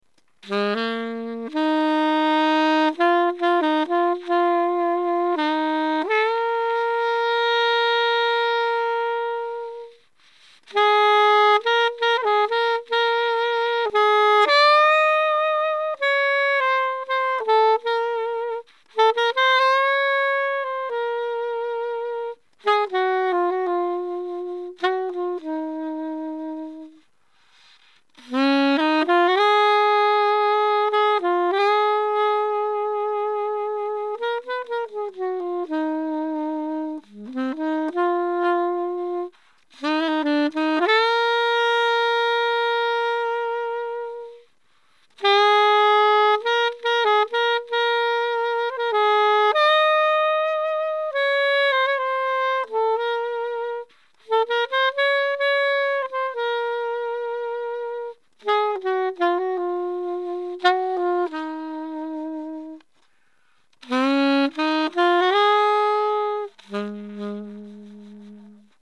黒のコンパウンドプラスチック製で、張りのある独特の甘みが特徴です。ぱりっとした音でビバップを吹くことも出来ます。
サンプル音源　IWサテンアルト